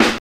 CRACKER.wav